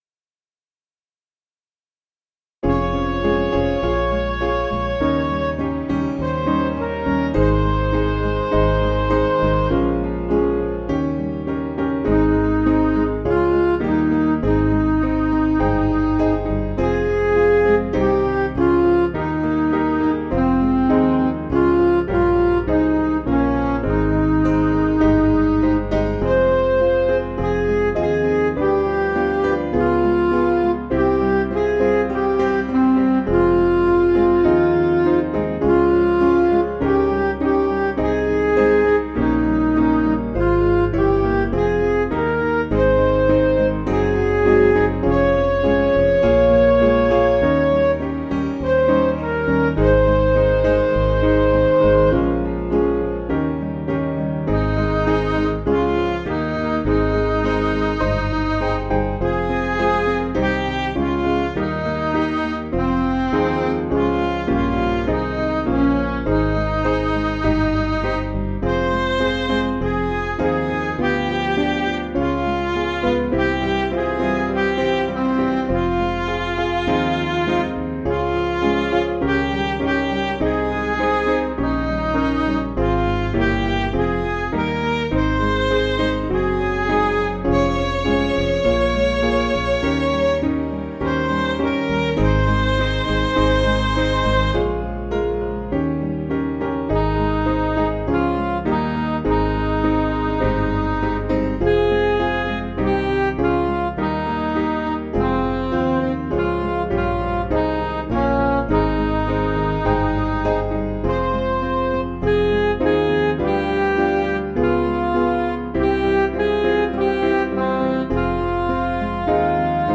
Piano & Instrumental
(CM)   6/Ab